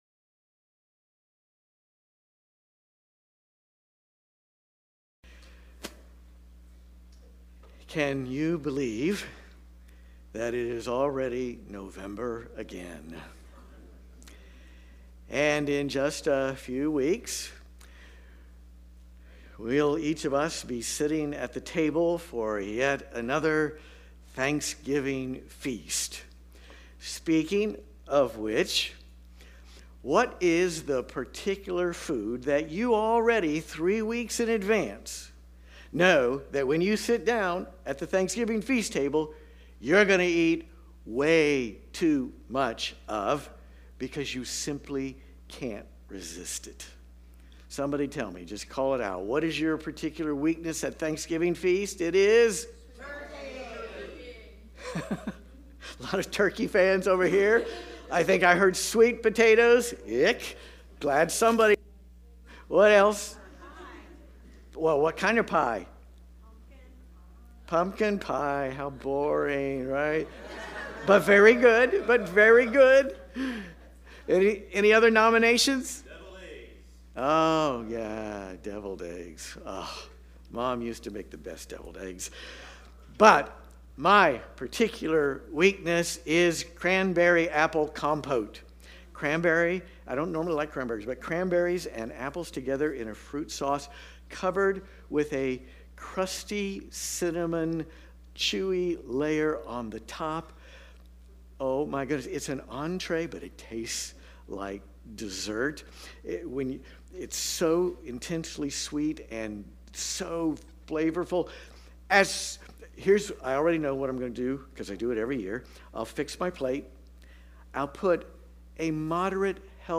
Watch the entire Worship Service